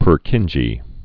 (pûr-kĭnjē)